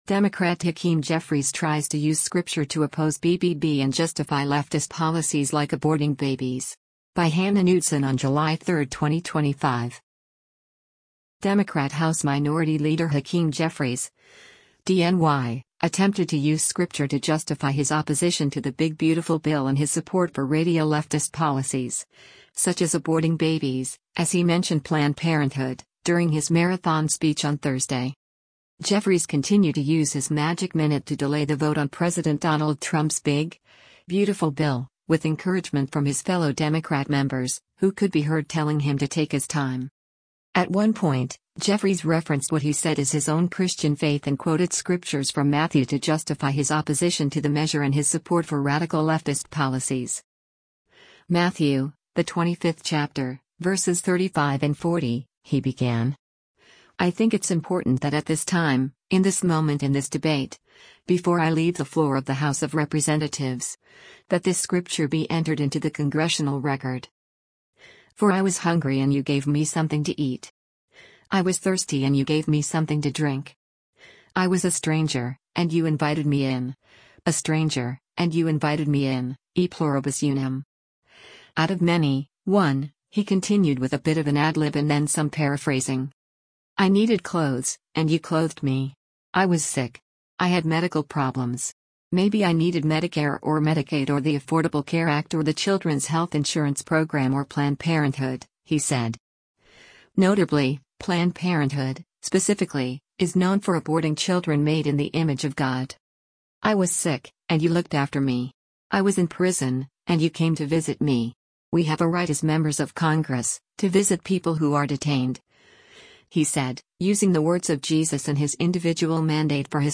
Democrat House Minority Leader Hakeem Jeffries (D-NY) attempted to use scripture to justify his opposition to the Big Beautiful Bill and his support for radial leftist policies — such as aborting babies, as he mentioned Planned Parenthood — during his marathon speech on Thursday.
Jeffries continued to use his “Magic Minute” to delay the vote on President Donald Trump’s big, beautiful bill, with encouragement from his fellow Democrat members, who could be heard telling him to take his time.